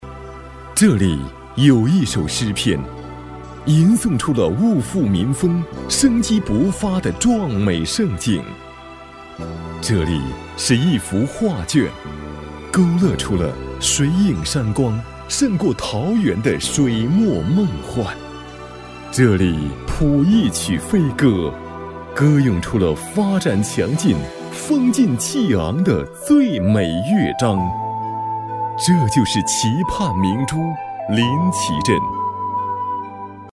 政府男101号（大气庄重）
大气浑厚男音，声线偏大年纪、沉稳庄重。